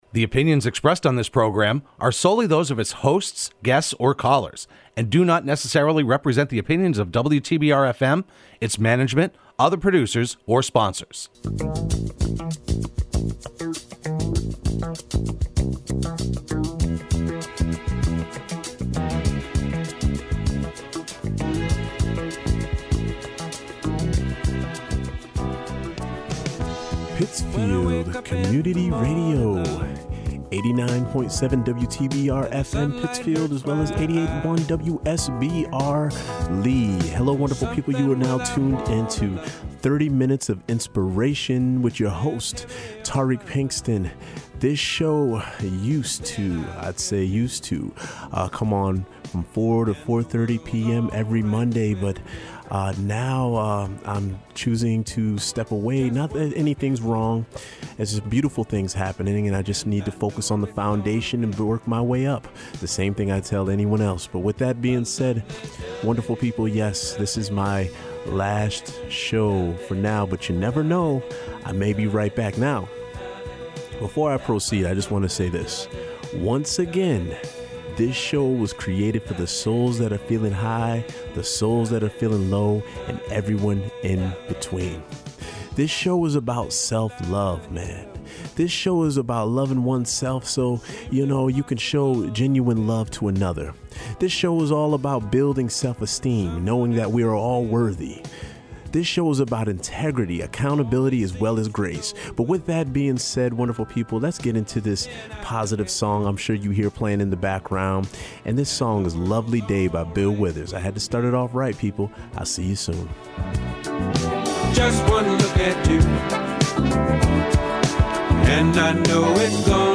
broadcast live every Monday at 4pm